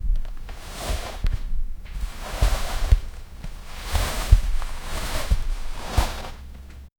Royalty-free decoration sound effects
unrolling--carpet-on-a-mnfnagyf.wav